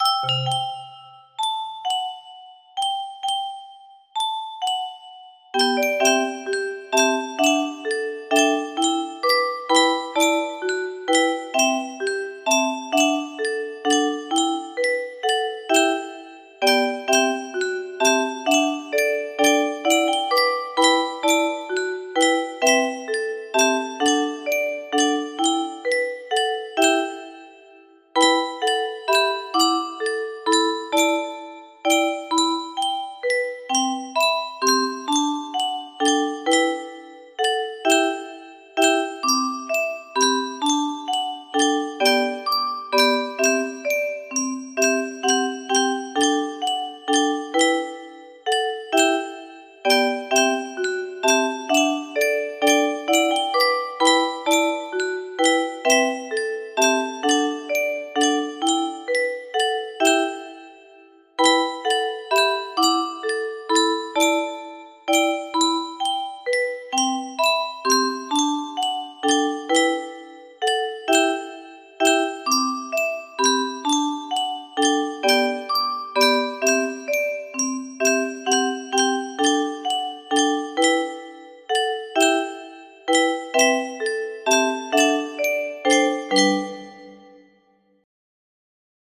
Lully, Lulla, Lullay - Stopford music box melody